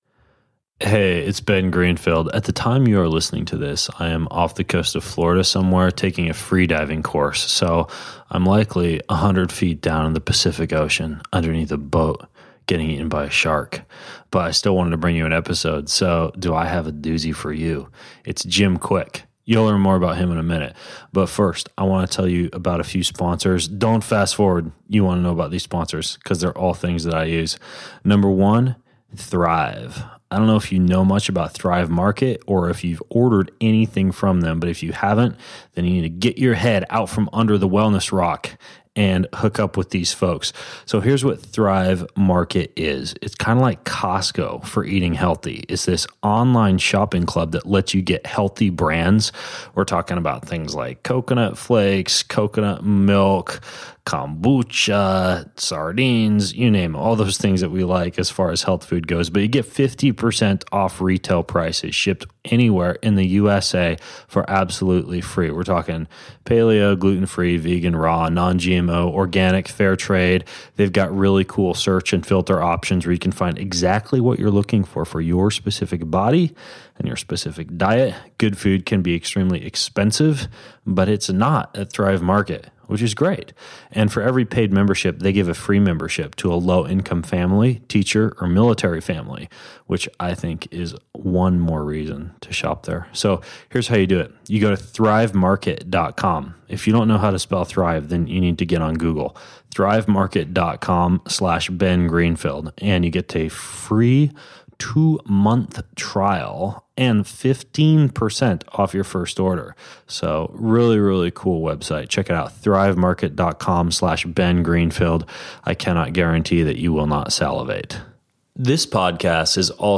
My guest in today’s podcast is Jim Kwik (yes, that’s his real name).